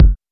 DrKick77.wav